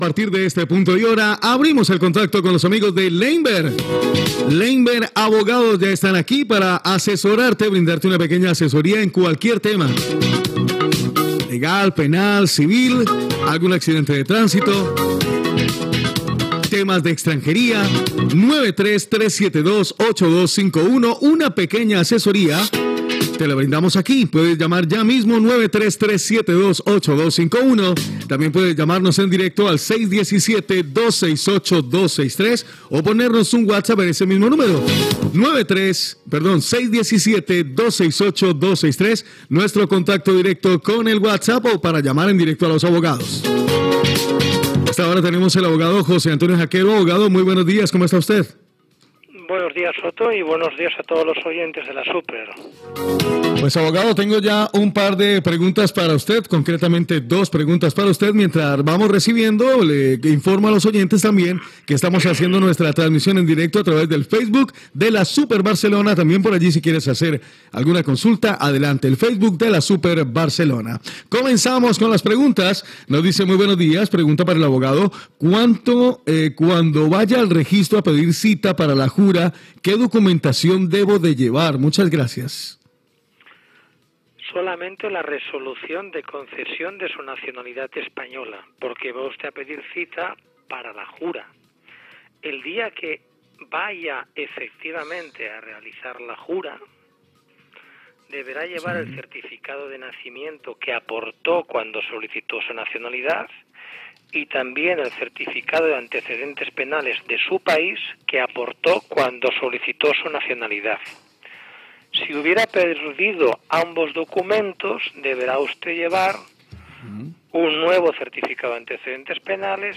Espai de Leynver Abogados, telèfons de participació, preguntes sobre temes legals i respostes d'un advocat
Entreteniment